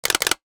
NOTIFICATION_Click_14_mono.wav